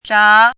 chinese-voice - 汉字语音库
zha2.mp3